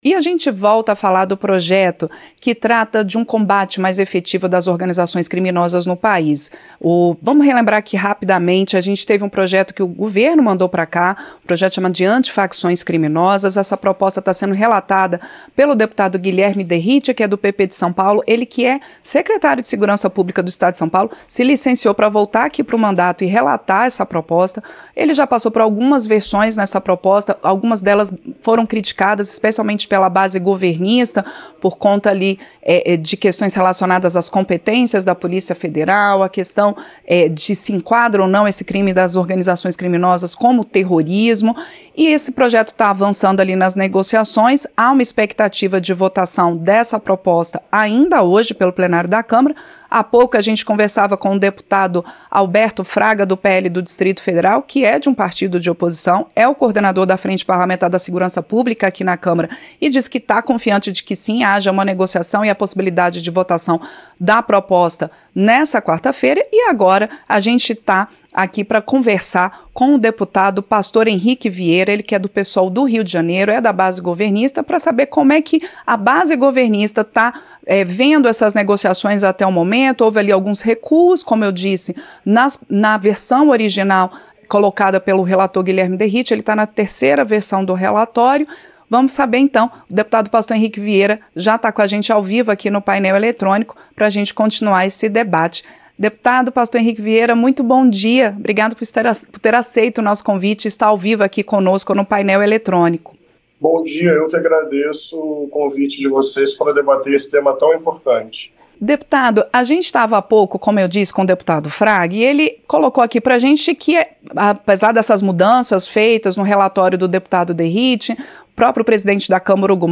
Entrevista -Dep.